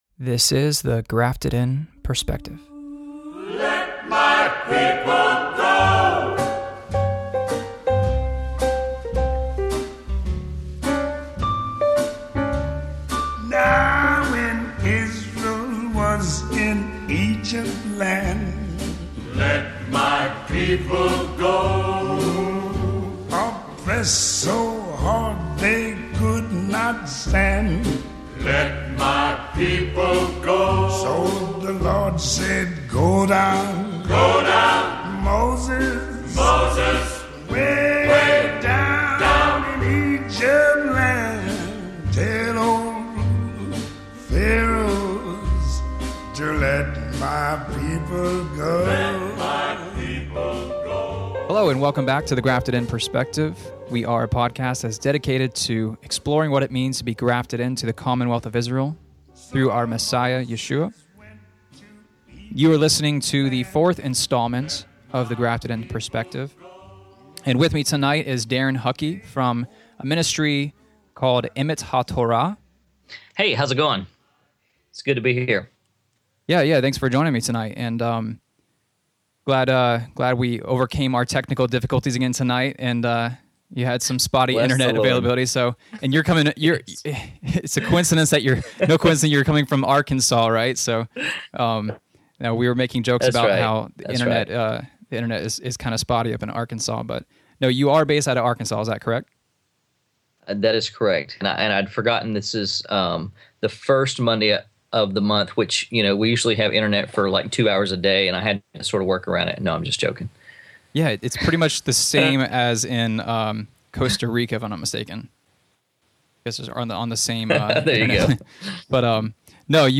Interview with The Grafted In Perspective on Discipleship | Emet HaTorah